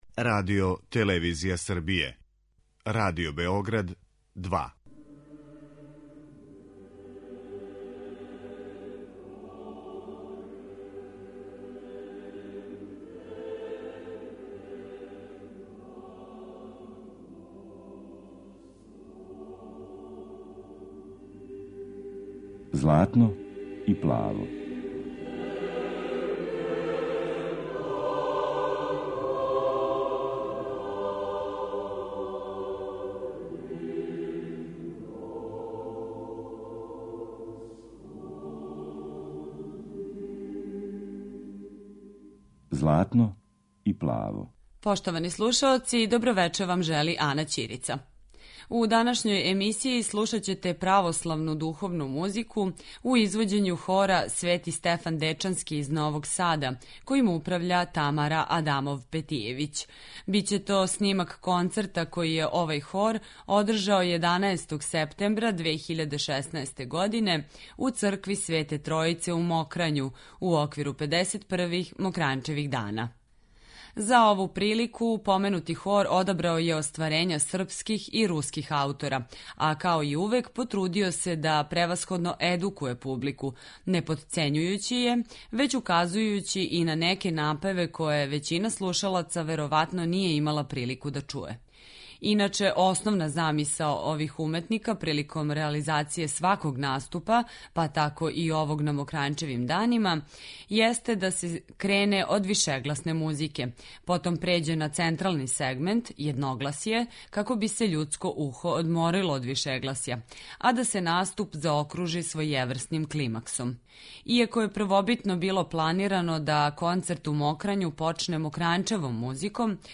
У данашњој емисији слушаћете православну духовну музику у извођењу Хора 'Свети Стефан Дечански' из Новог Сада
Биће то снимак концерта који су ови уметници одржали 11. септембра 2016. године у Цркви Свете Тројице у Мокрању, у оквиру 51. Мокрањчевих дана.